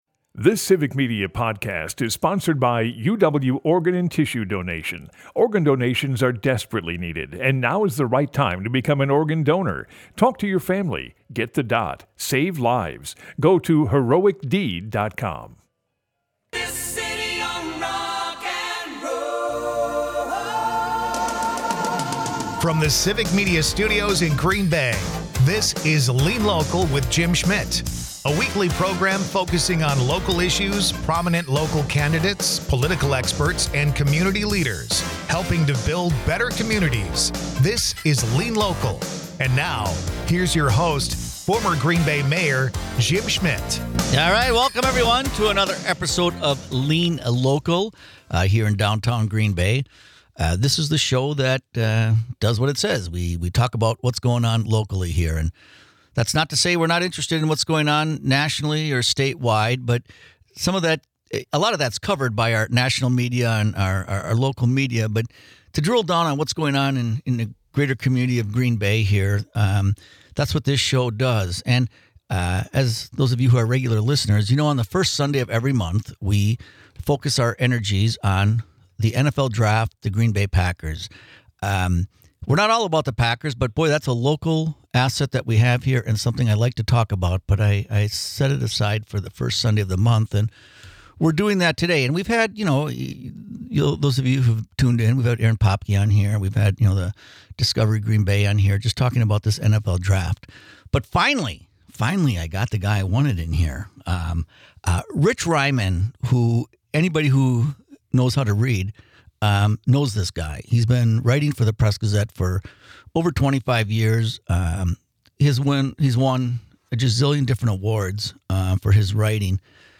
Dive into the heart of community issues with 'Lean Local,' hosted by former Green Bay Mayor Jim Schmitt. This refreshing political and issues program bypasses traditional left-versus-right rhetoric.